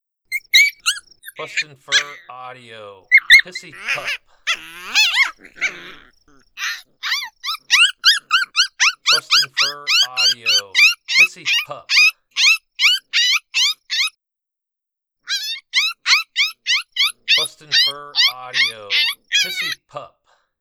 Small coyote pup distress, excellent sound for year around use.